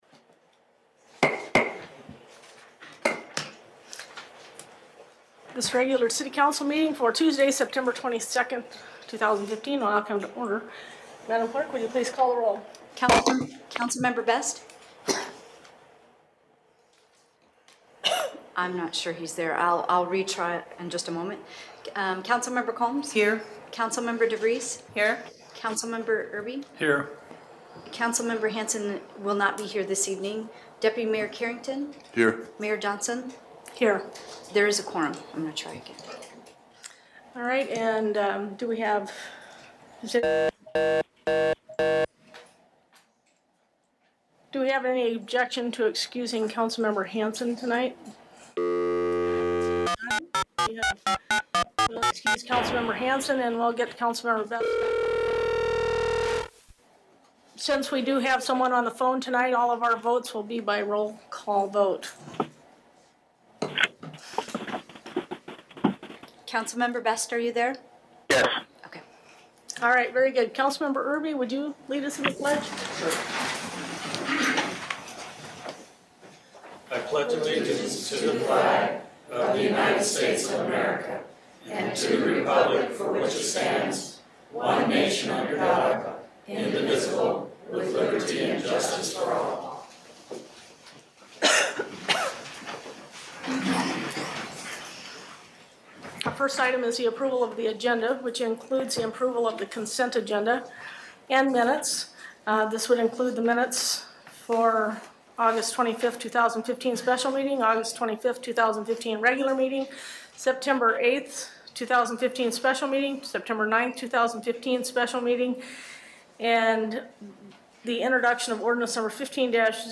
Palmer City Council Meeting 9.22.2015